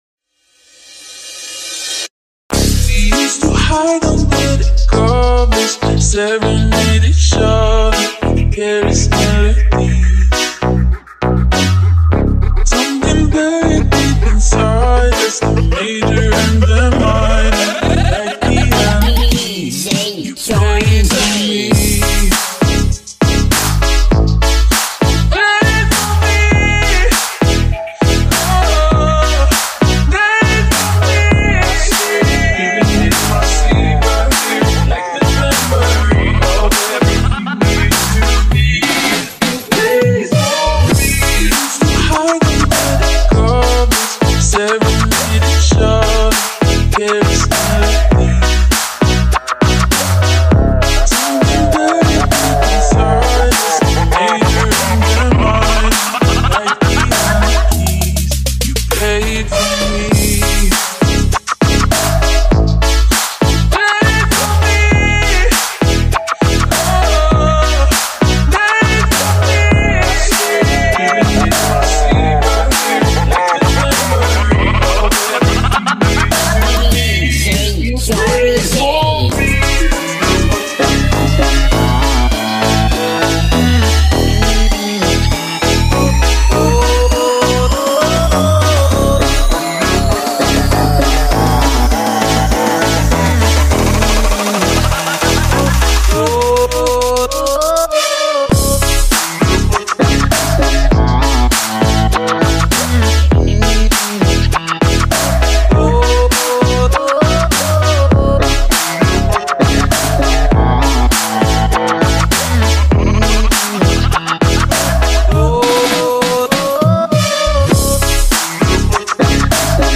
SLOWED BASS